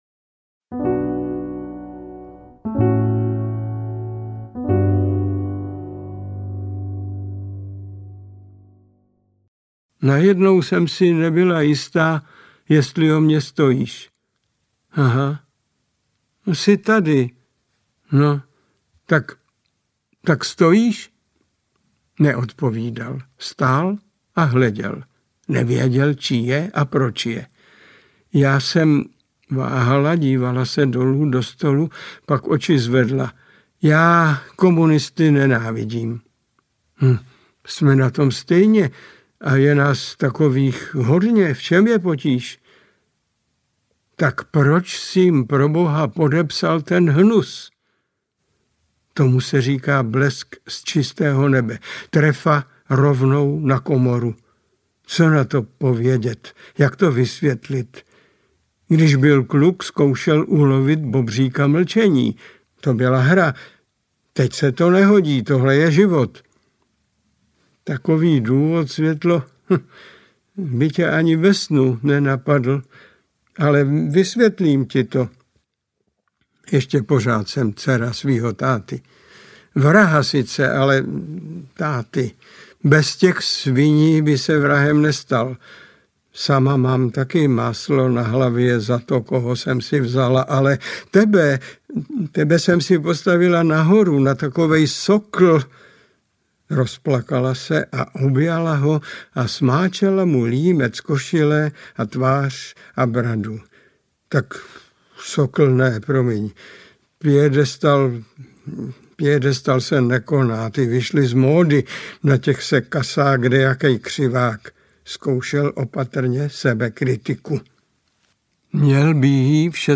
Pokrývač audiokniha
Ukázka z knihy
• InterpretMiloň Čepelka